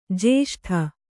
♪ jeṣṭha